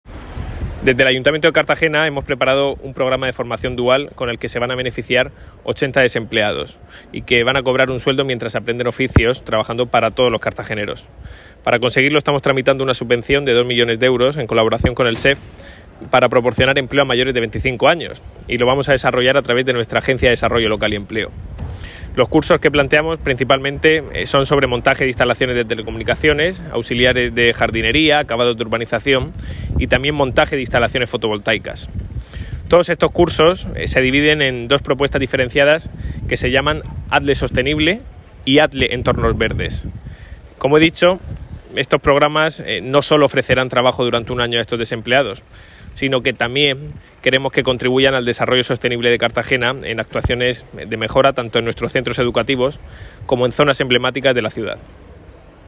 Enlace a Declaraciones de Ignacio Jáudenes